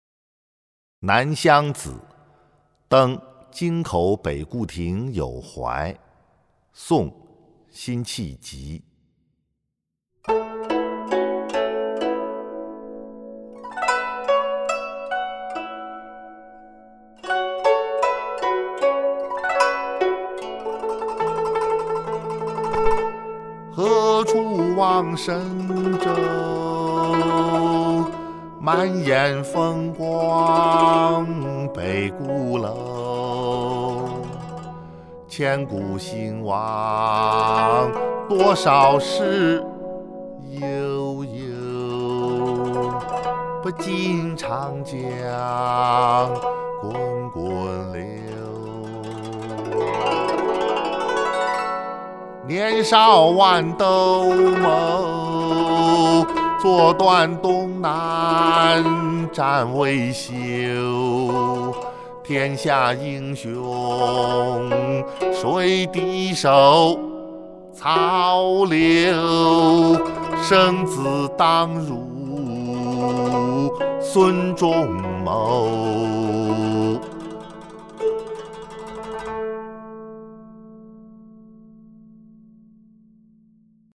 【宋】辛弃疾 《南乡子·登京口北固亭有怀》（吟咏）